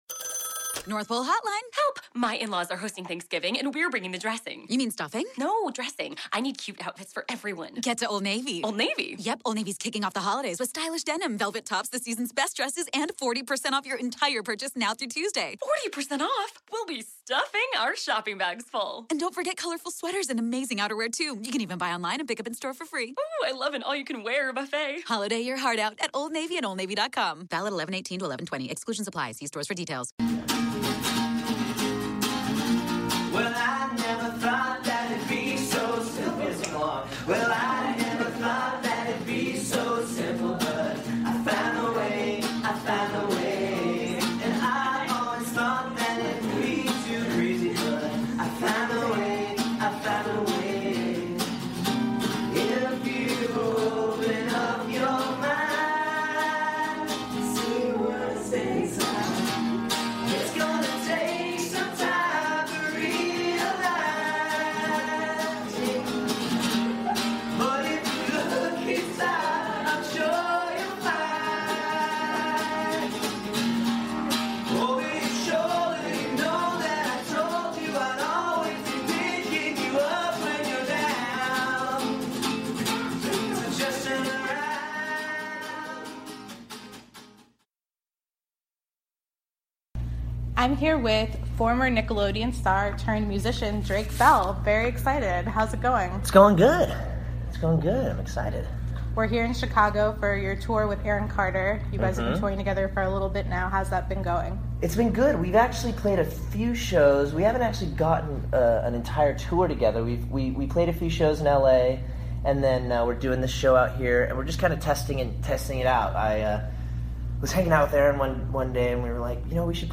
Drake Bell Interview